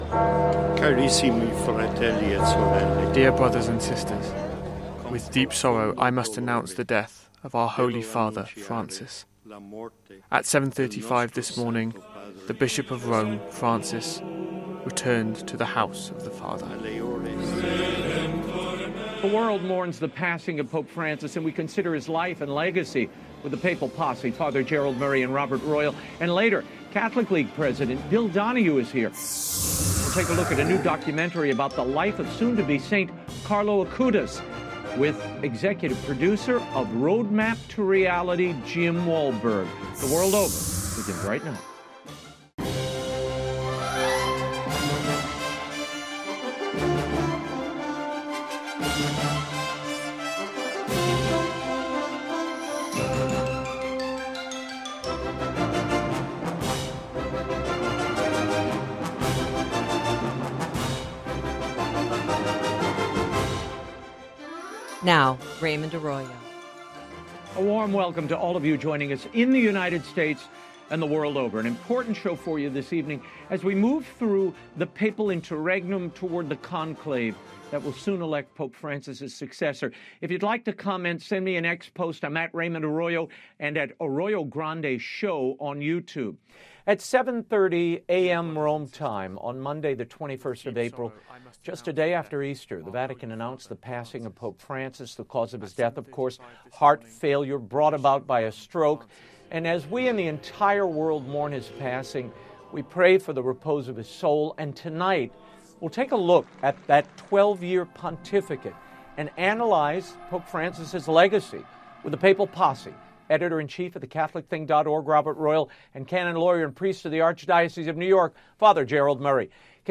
Kicking off the new year with a replay episode from our powerful interview with Dr. Stan Tatkin, this discussion dives into inner workings of relationships from a biological and societal perspective, and his book, In Each Other’s Care.